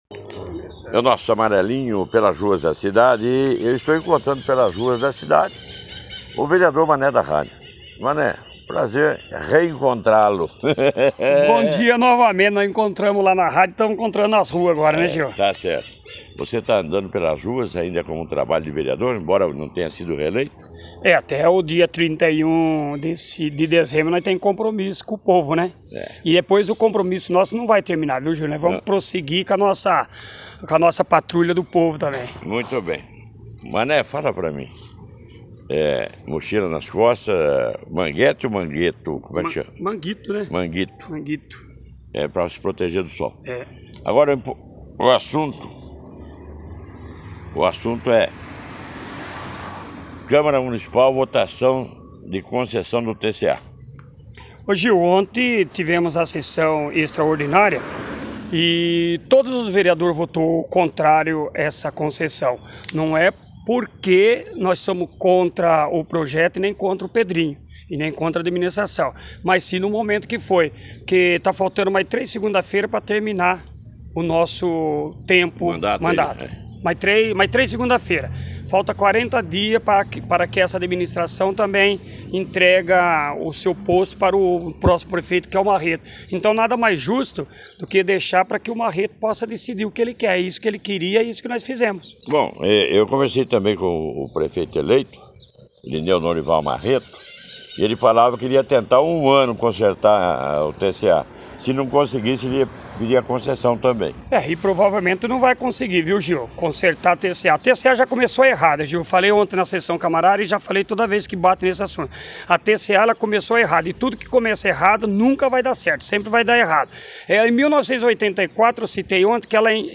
Saiba mais com o repórter